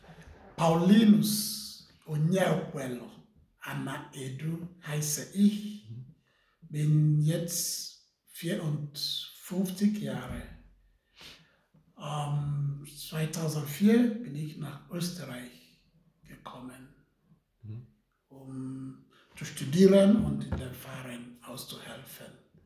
Hartkirchen in Oberösterreich, samstagsabends um 18.55 Uhr. In der Kirche haben sich rund 40 Menschen eingefunden.
„Guten Abend“, schallt es im Chor zu ihm zurück.